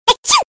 دانلود صدای عطسه مرد 2 از ساعد نیوز با لینک مستقیم و کیفیت بالا
جلوه های صوتی